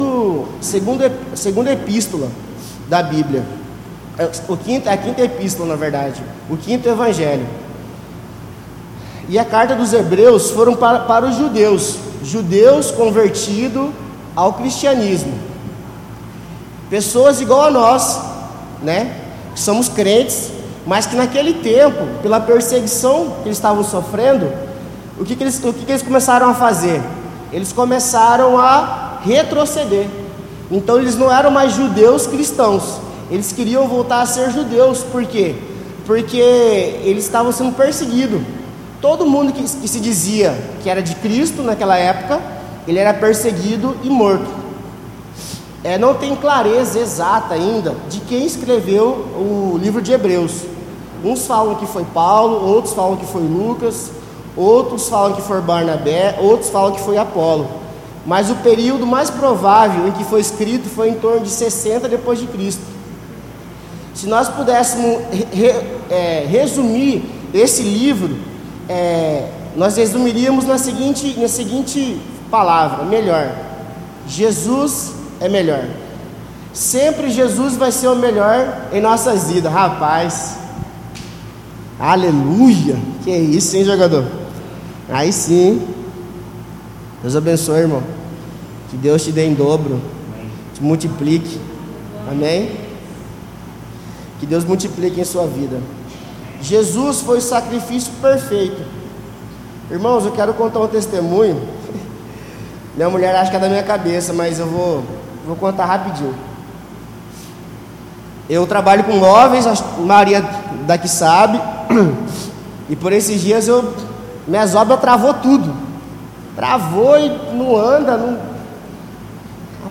Em Culto de Celebração